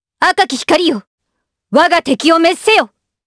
Chrisha-Vox_Skill1_jp.wav